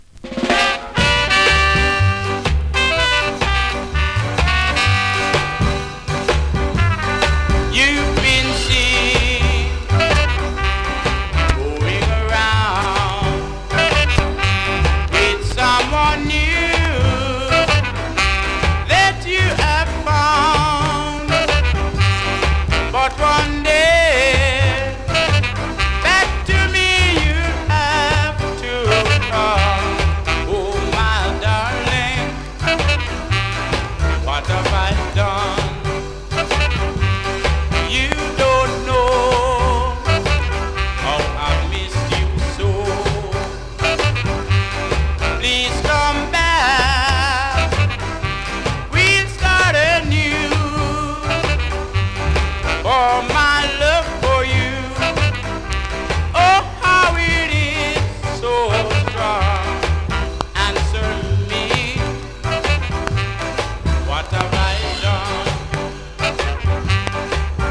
※出だしで一瞬針飛びしそうなキズ有